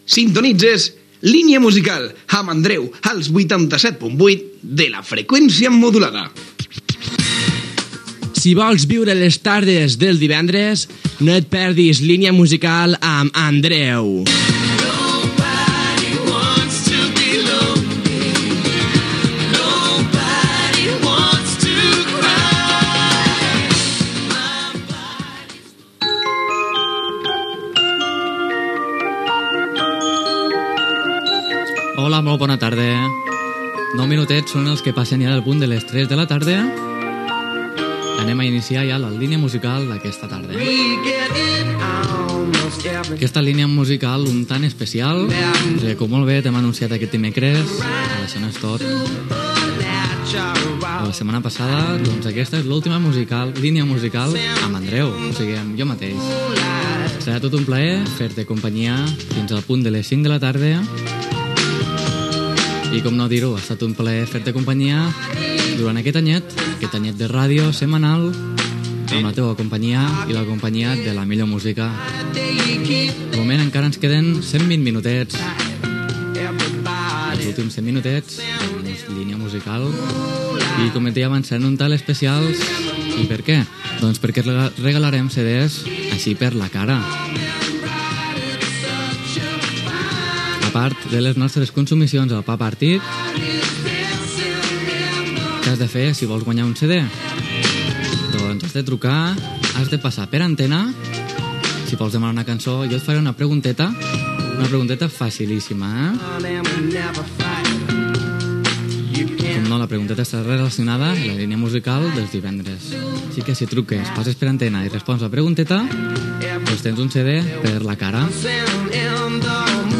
Careta i presentació de l'últim programa amb l'anunci del concurs per guanyar un CD.
Musical
FM